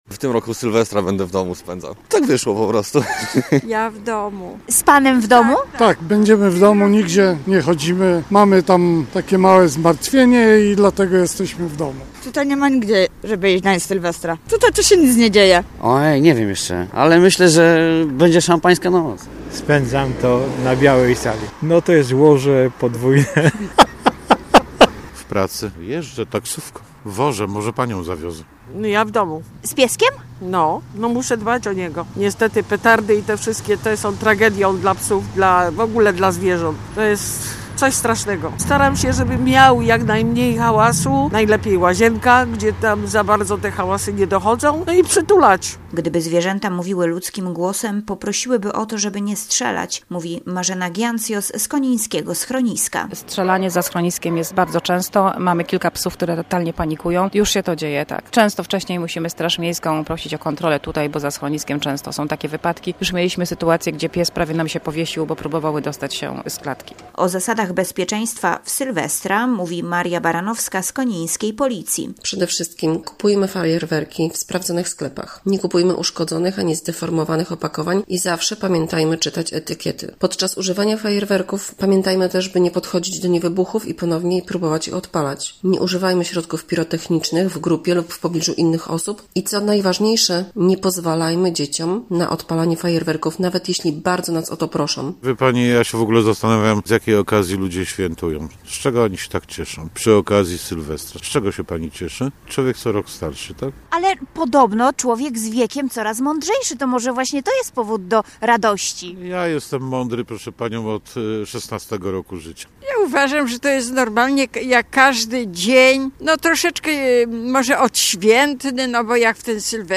Nasza reporterka sprawdziła, jakie plany mają na ten czas mieszkańcy Konina.
- mówią mieszkańcy.